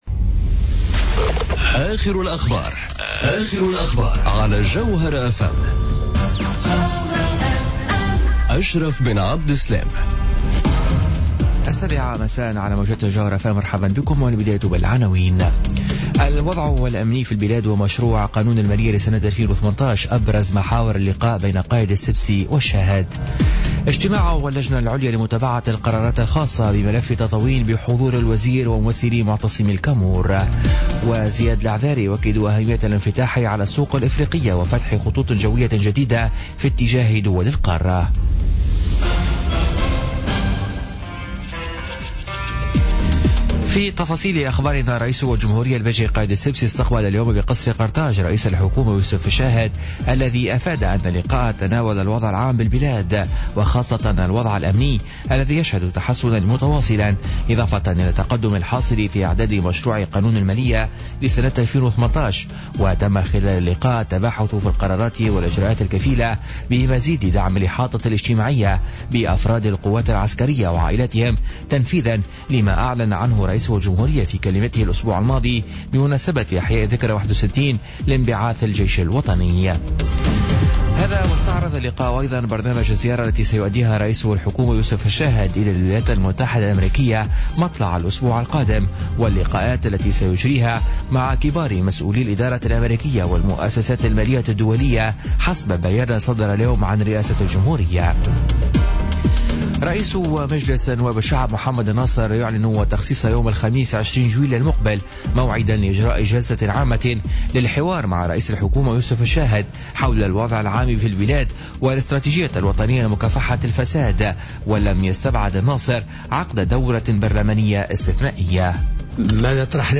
نشرة أخبار السابعة مساء ليوم الخميس 06 جويلية 2017